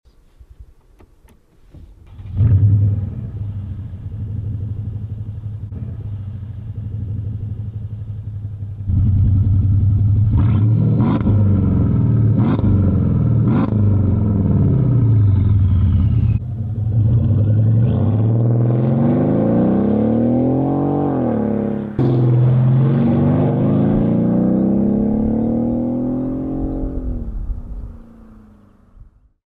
‘12 Mercedes-Benz CL 500 with MEC Design Earthquake exhaust!